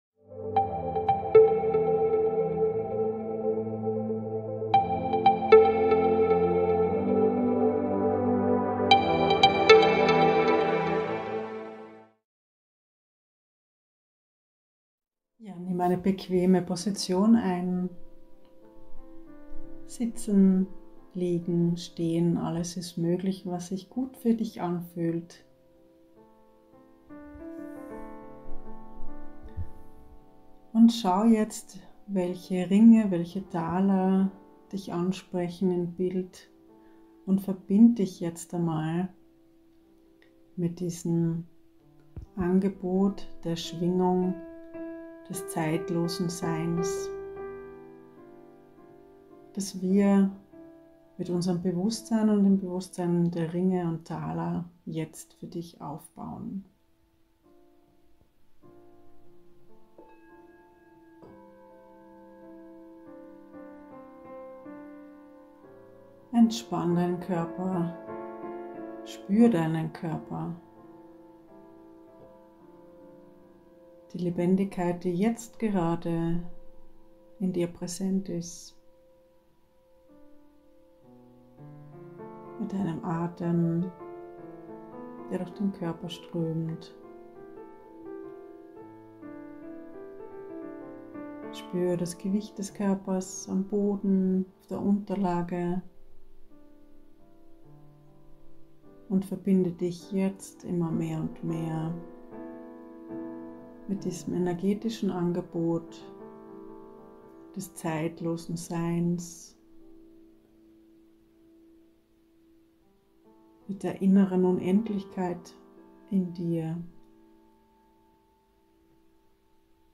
Tauche jetzt ein in die Erfahrung der grenzenlose Unendlichkeit deiner Existenz mit dieser besonderen Klang- und Energiearbeit.
piano
Kristallklangschalen